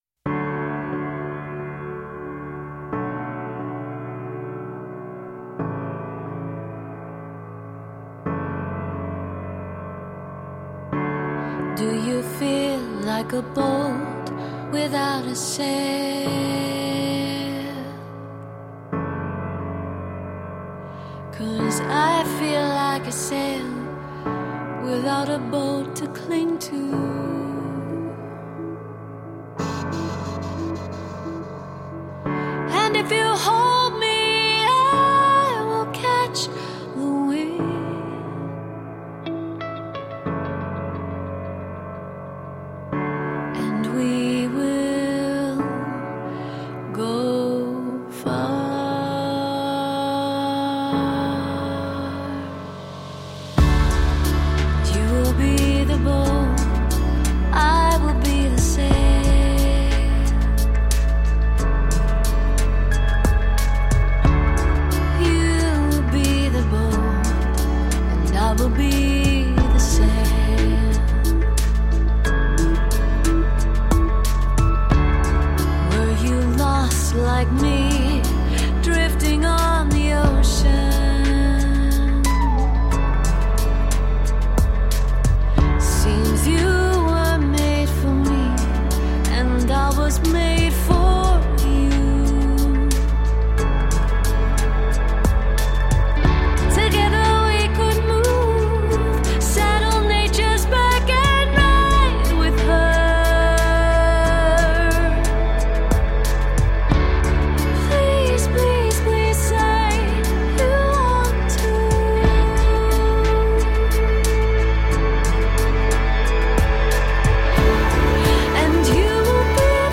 Melodic, eclectic 'quirk-pop'.
The subtle and unusual addition is, of course, a ukulele.
Tagged as: Alt Rock, Pop, Singer songwriter